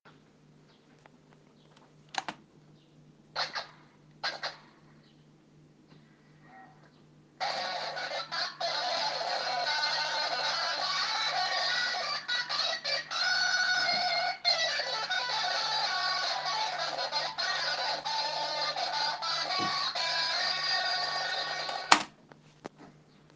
Amfi ile kayıt alırken cızırtı problemi
Yeni öğrendiğim bi bilgiye göre benim amfimden gitar kaydı alınabiliyormuş.
Amfimi usb ile bilgisayara bağlayıp kayıt almaya başladığımda inanılmaz bir cızırtı geliyor ve gitarın kendi sesi neredeyse duyulmuyor.